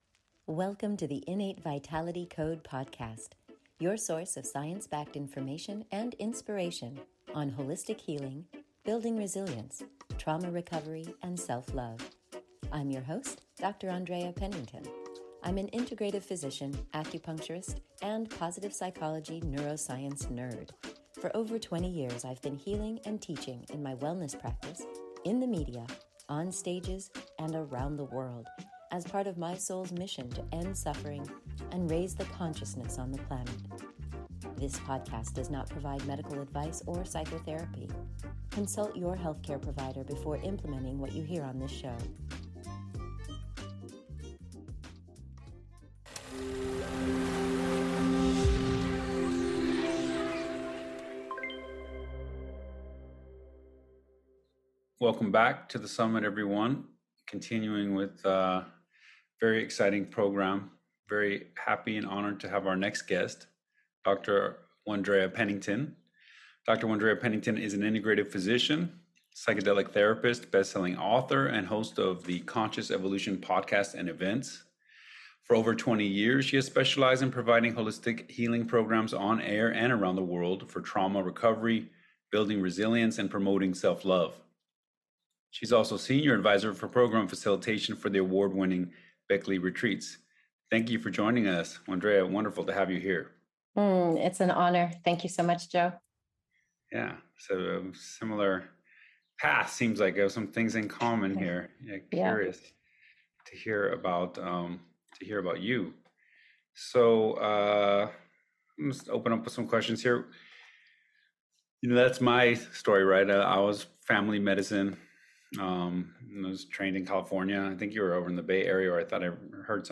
This interview is part of the Psychedelic Healing Summit, a free online event.